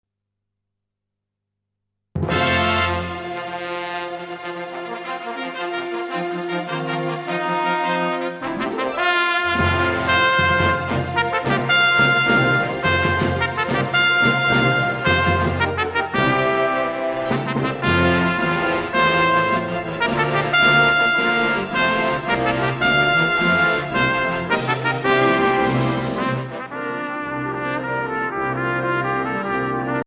Voicing: Trumpet Collection